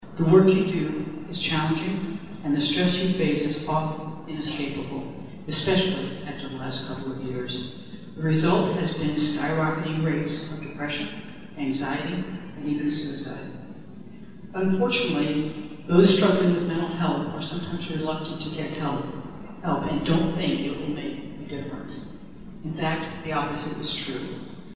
Governor Kelly addresses the 2022 Kansas Ag Summit in Manhattan